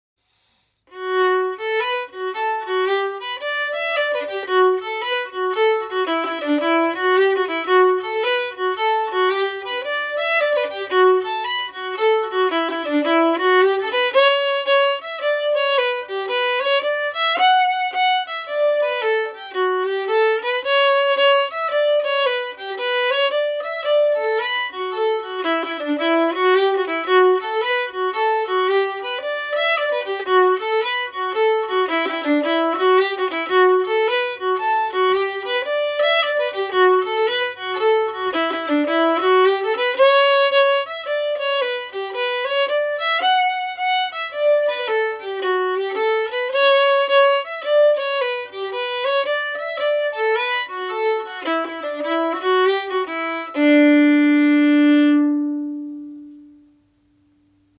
The Snowy Path with slurs (MP3)Download
the-snowy-path-with-slurs.mp3